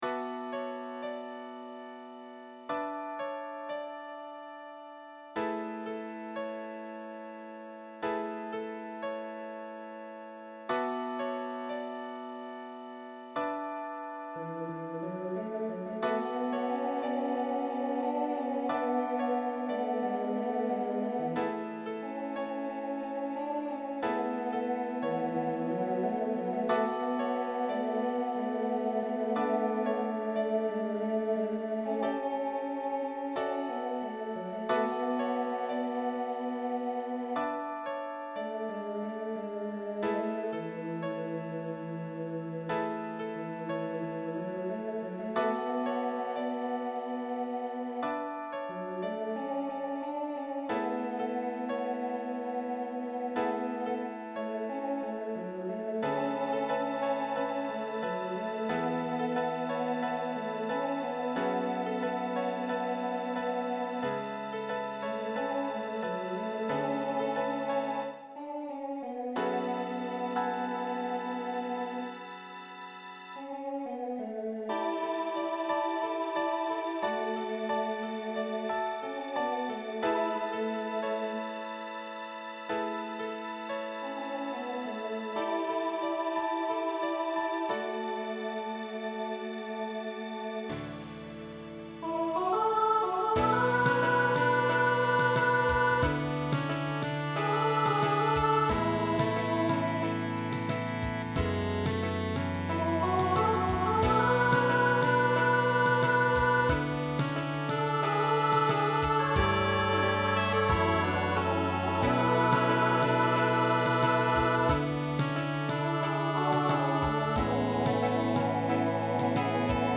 SATB Instrumental combo
Broadway/Film
Ballad